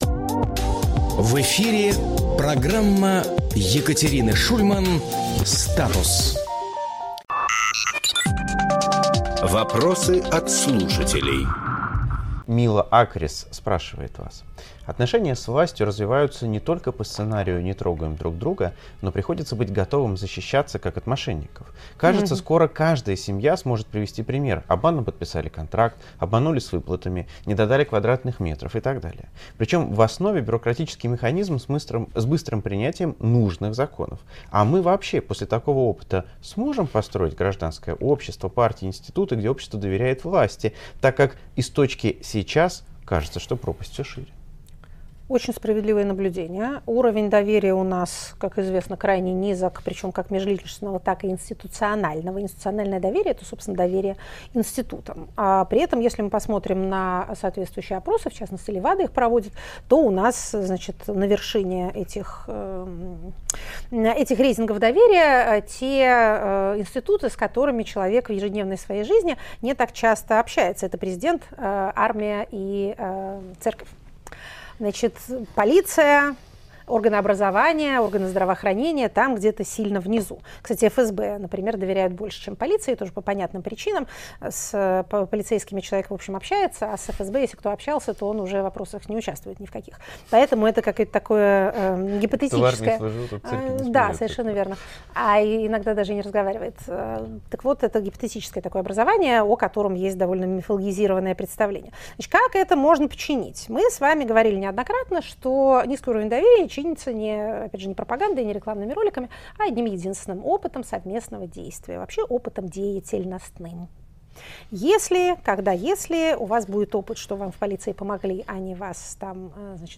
Екатерина Шульманполитолог
Фрагмент эфира от 14 января.